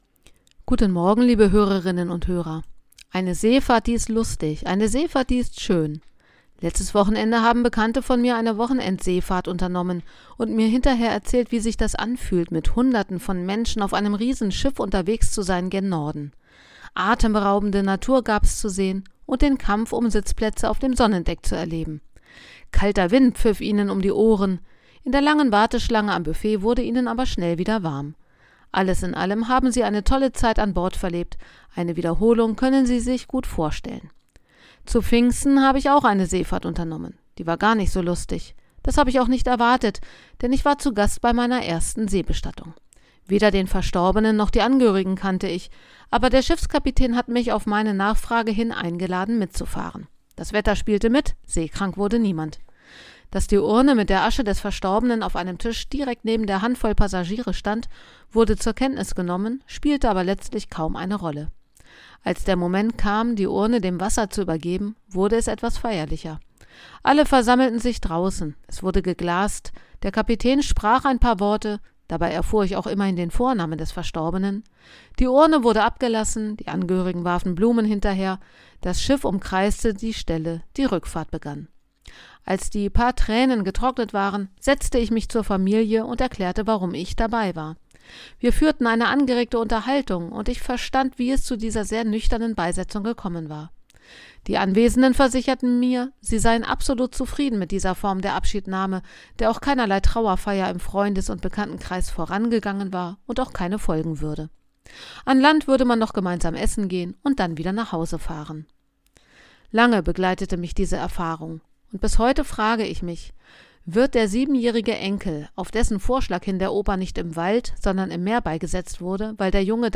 Radioandacht vom 22. August